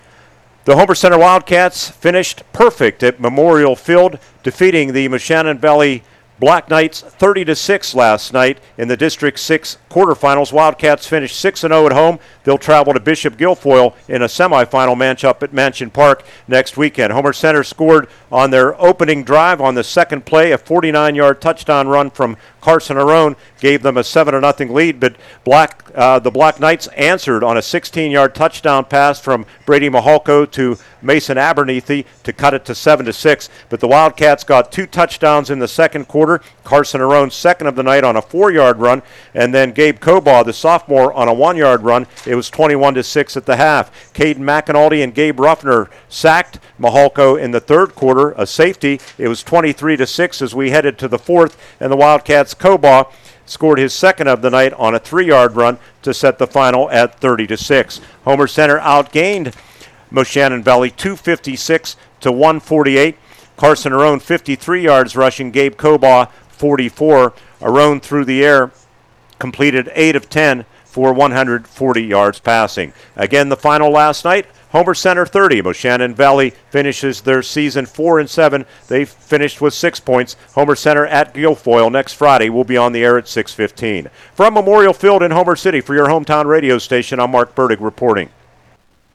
had the recap on WCCS and Renda Digital TV